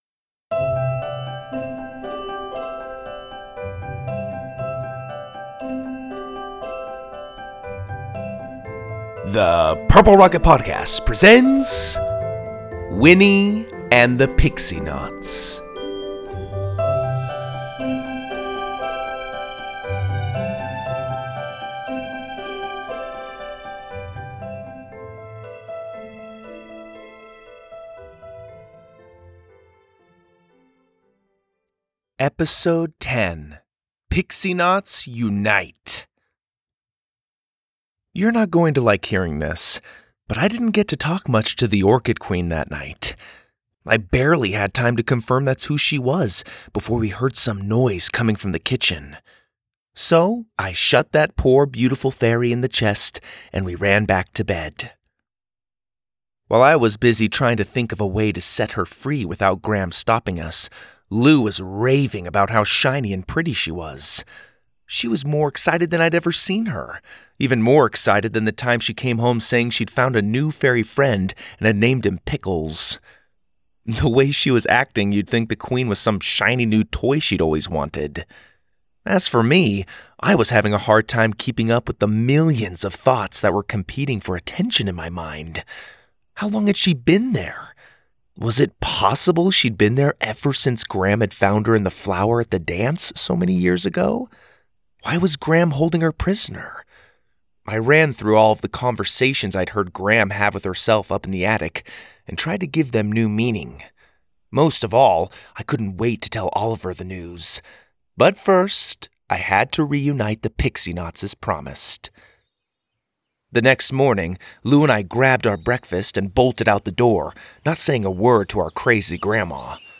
Stories For Kids, Kids & Family